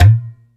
TABLA 2.WAV